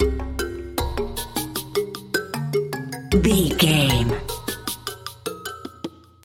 Aeolian/Minor
bongos
congas
hypnotic
medium tempo